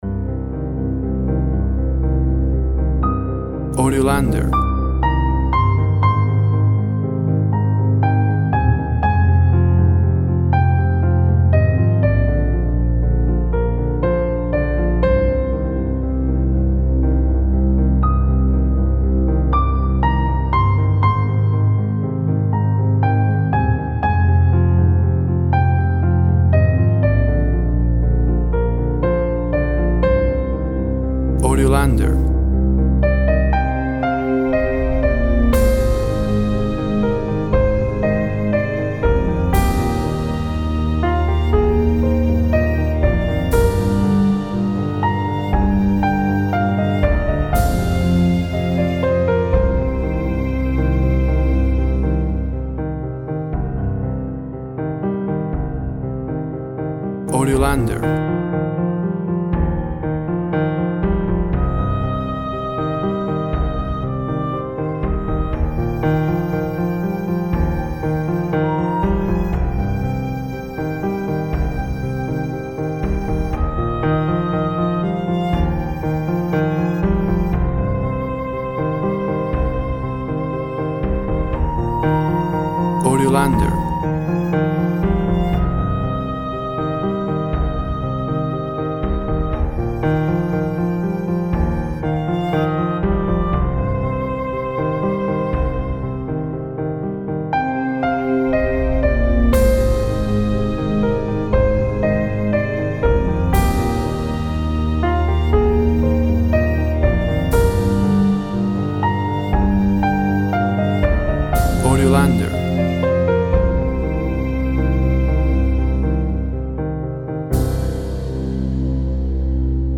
Melancolic with an emotive arising end.
Tempo (BPM) 58